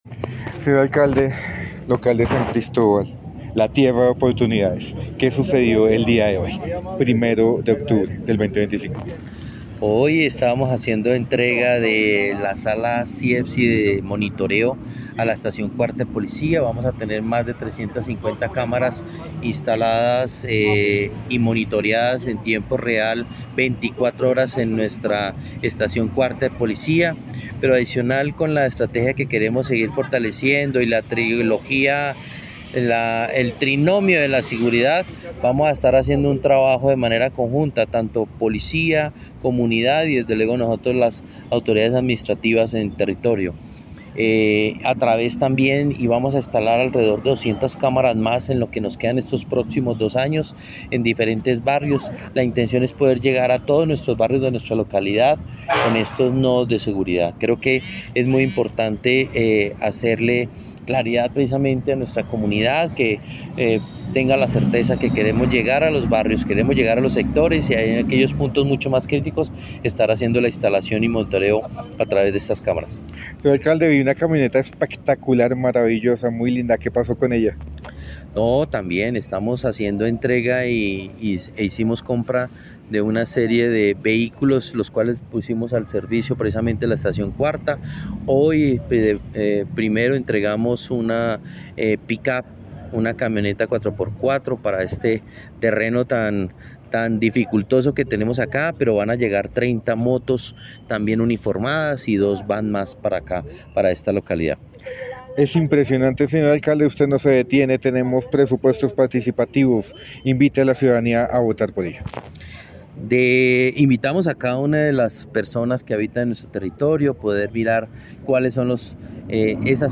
Entrevista con el alcalde Carlos Macías de la localidad 4 de San Cristóbal